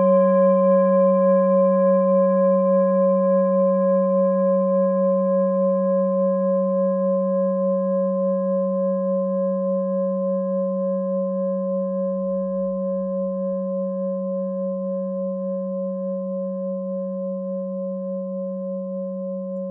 Klangschale Bengalen Nr.14
Sie ist neu und wurde gezielt nach altem 7-Metalle-Rezept in Handarbeit gezogen und gehämmert.
(Ermittelt mit dem Filzklöppel)
In unserer Tonleiter liegt dieser Ton nahe beim "Fis".
klangschale-ladakh-14.wav